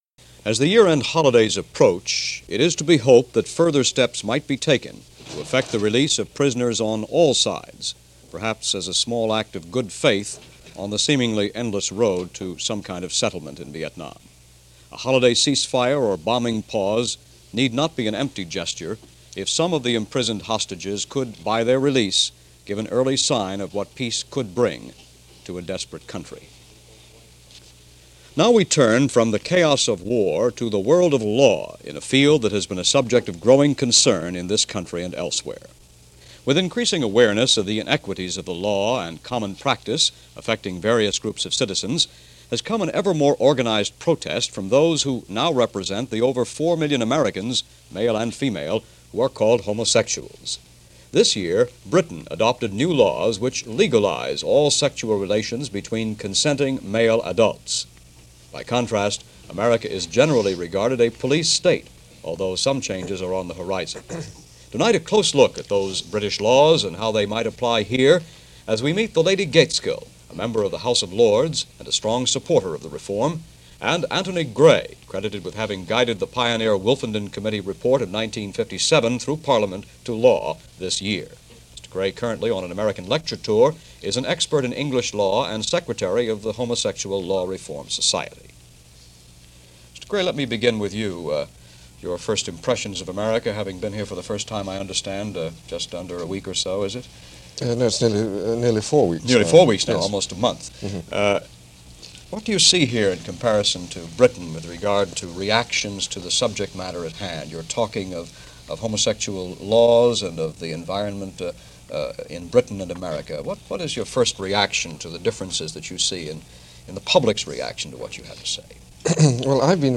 Discussion on The Wolfenden Report